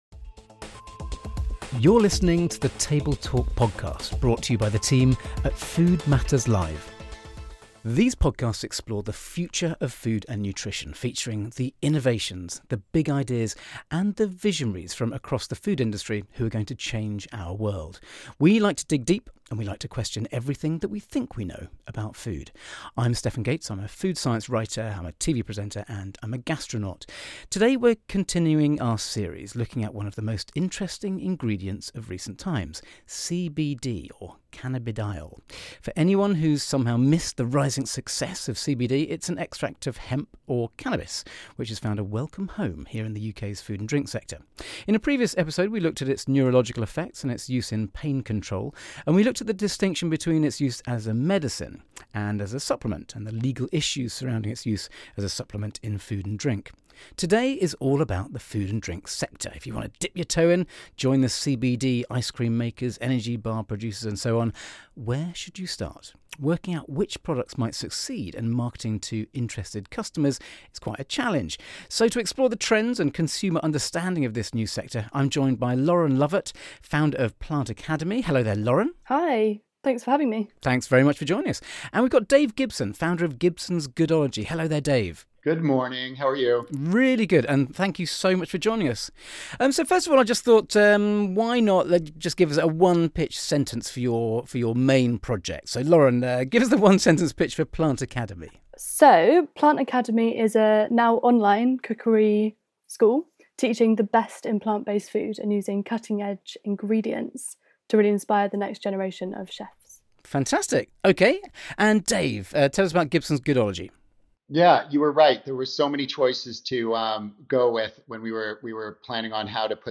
In this second Table Talk episode focussing on CBD, Stefan Gates is joined by two experts on how the supplement can be put to use.